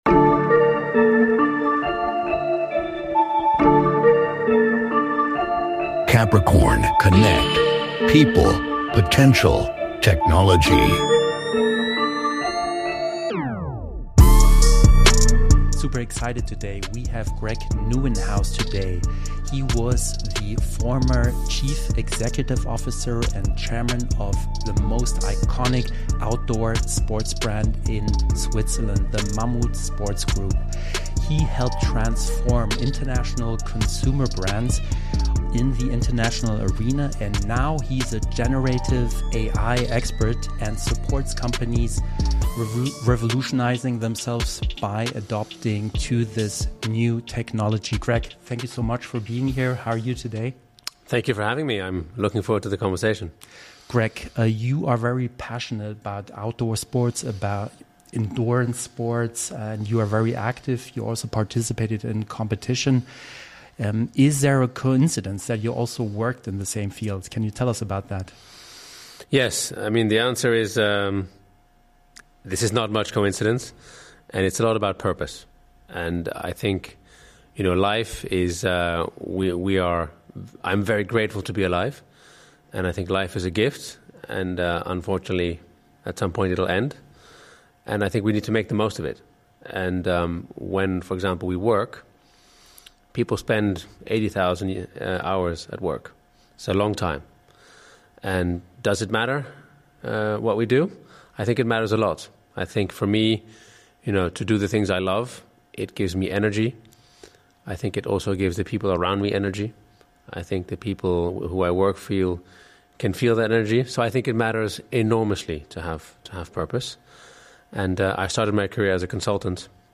An inspiring and candid conversation about transformation, leadership, perseverance, and the opportunities of artificial intelligence.